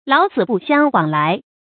注音：ㄌㄠˇ ㄙㄧˇ ㄅㄨˋ ㄒㄧㄤ ㄨㄤˇ ㄌㄞˊ
老死不相往來的讀法